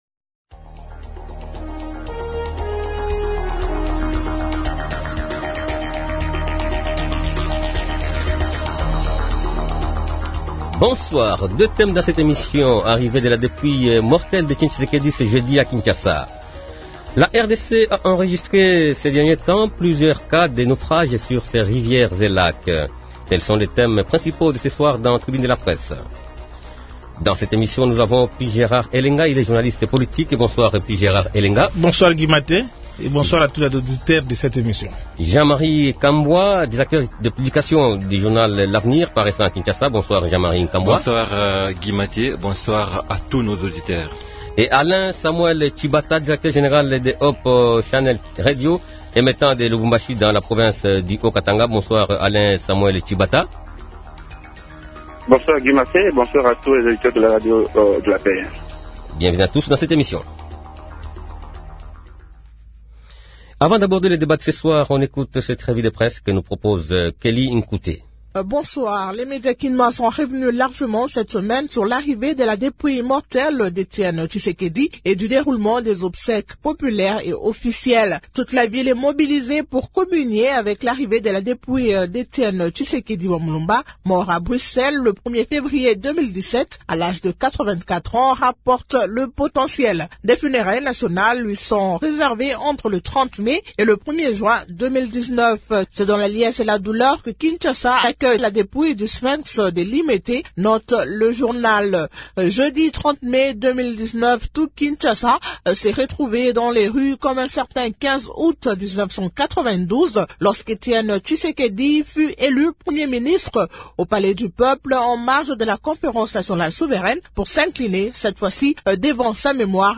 Deux thèmes dans cette émission :